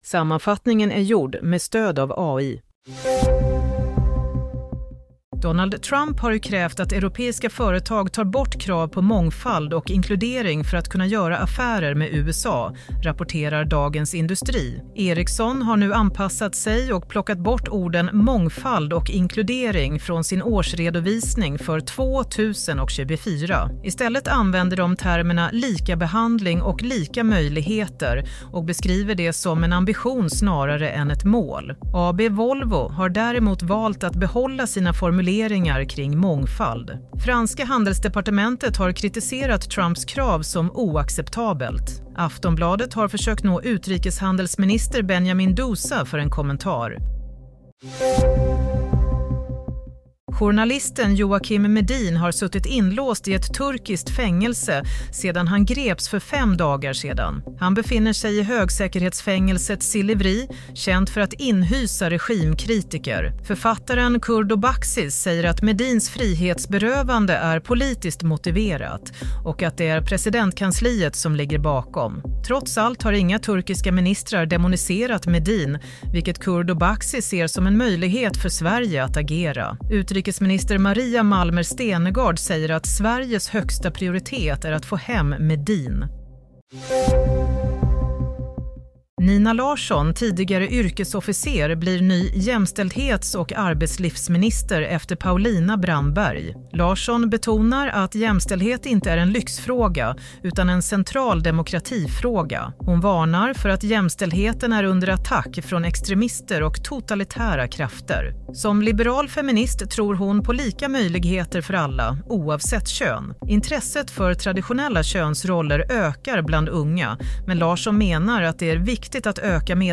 Nyhetssammanfattning - 1 april 23.15
Sammanfattningen av följande nyheter är gjord med stöd av AI.